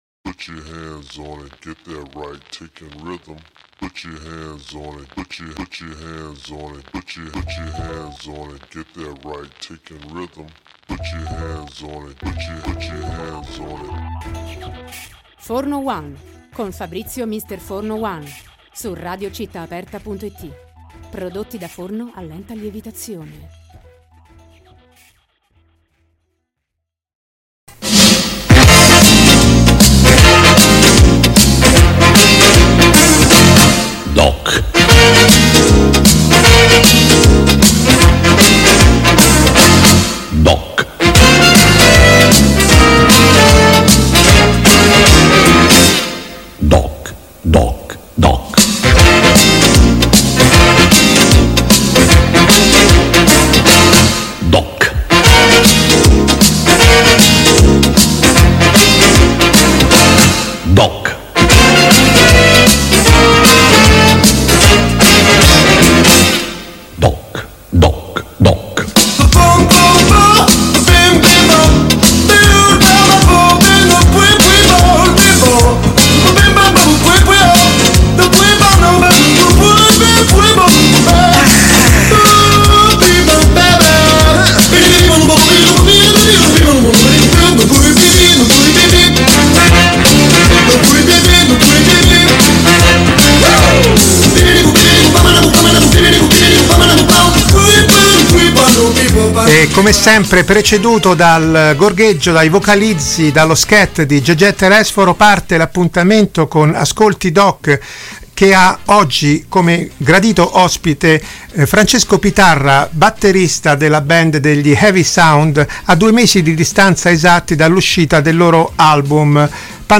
Ascolti DOC: intervista